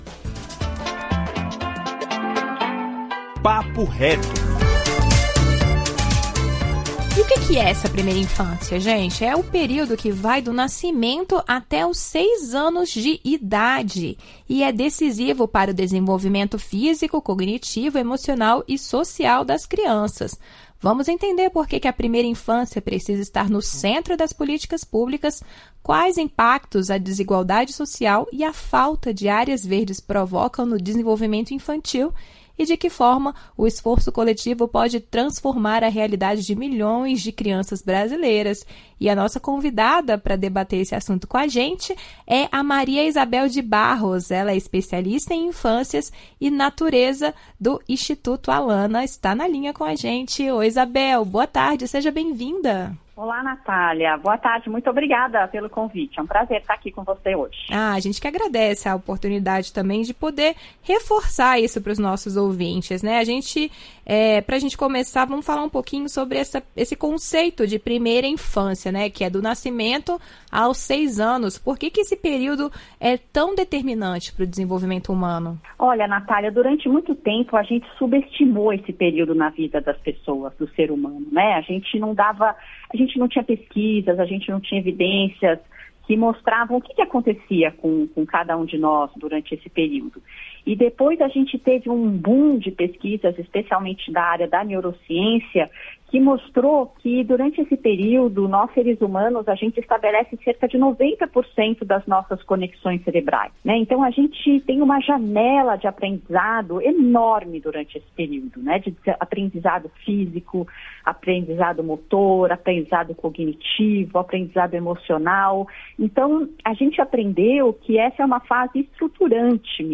Em entrevista ao Nacional Jovem